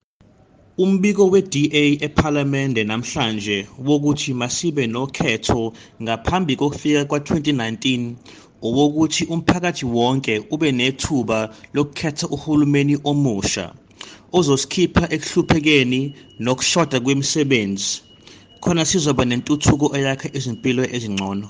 Issued by Zakhele Mbhele MP and Sejamothopo Motau MP – DA Shadow Minister of Police and DA Shadow Minister of the Presidency, Planning, Monitoring and Evaluation
isiZulu by our Speakers in the National Assembly.
Zakhele-Mbhele-IsiZulu.mp3